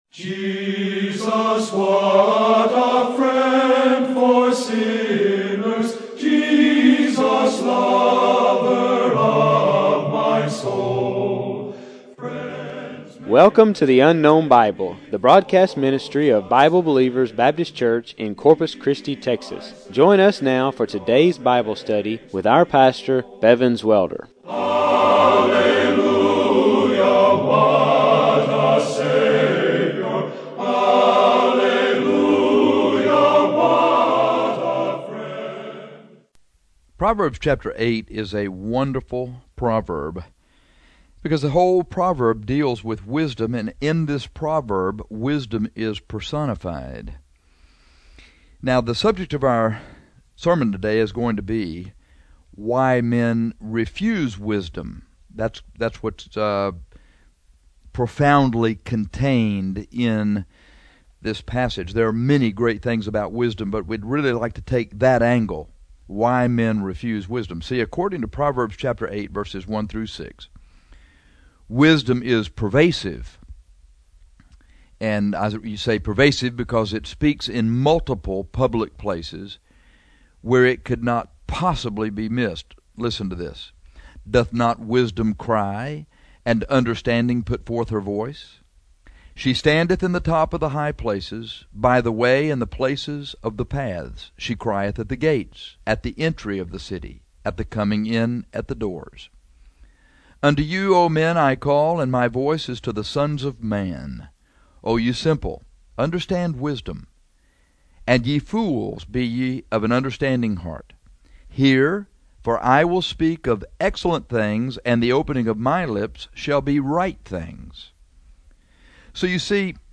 This sermon is on why men refuse wisdom in spite of her great promises to reward them more handsomely than any of their worldly pursuits.